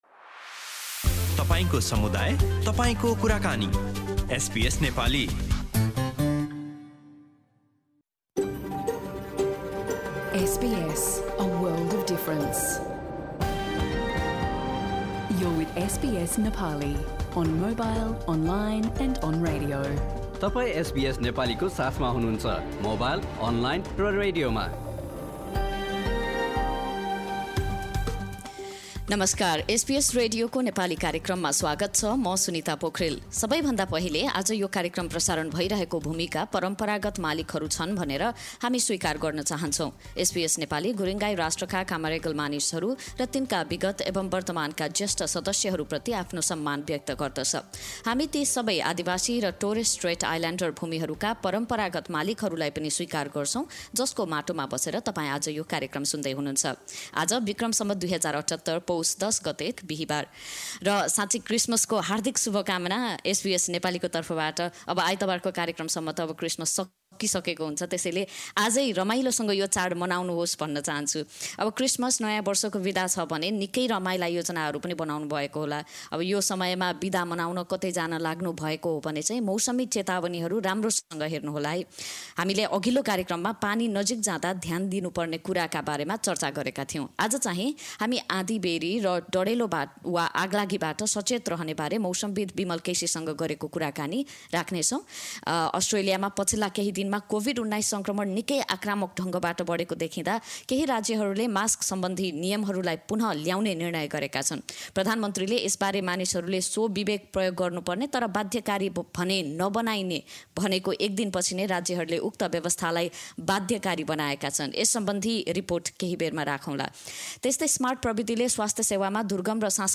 एसबीएस नेपाली रेडियो कार्यक्रम: बिहीबार २३ डिसेम्बर २०२१